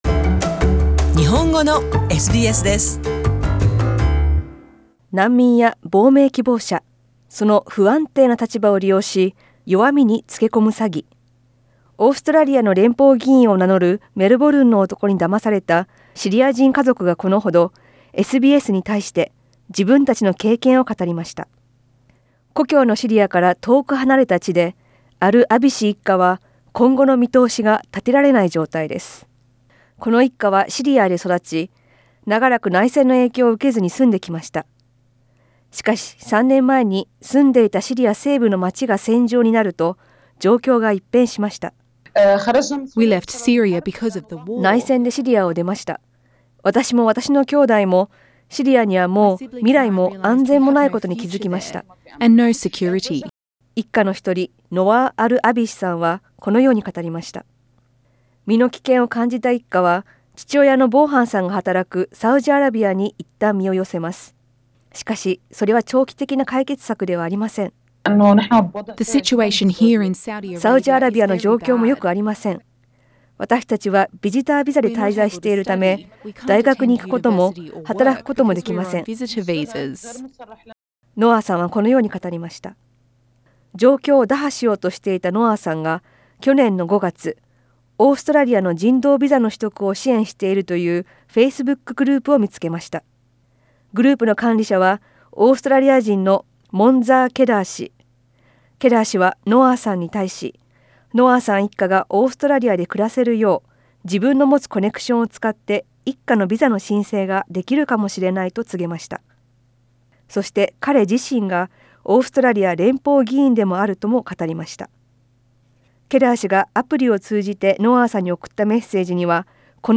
詳しくは写真をクリックして音声リポートをどうぞ。